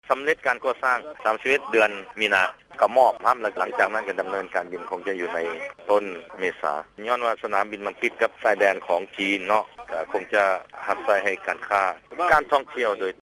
ຄາດວ່າ ຄົງຈະສາມາດ ເປີດໃຫ້ບໍຣິກາຣ ໄດ້ໃນໄວໆນີ້ ເພື່ອຮອງຮັບກາຣ ເຊື່ອມຕໍ່ດ້ານກາຣທ່ອງທ່ຽວ ກາຣຄ້າ ແລະກາຣລົງທຶນ ຣະຫວ່າງຈີນຕອນໃຕ້ ກັບໂຂງເຂຕອາຊ໊ຽນ ຕໍ່ໄປໃນອະນາຄົດ ດັ່ງທີ່ ທ່ານ ຢາກົວ ລໍປາງກາວ ຫົວໜ້າກົມ ກາຣບິນພົລເຮືອ ນຂອງລາວໄດ້ກ່າວວ່າ: